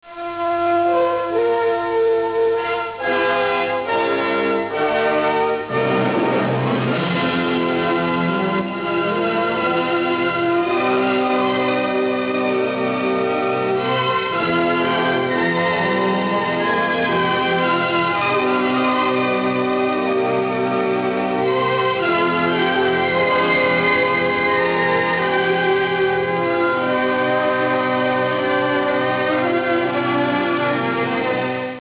Colonna sonora
molto passionale, romantico, di arie ebraiche
Original track music: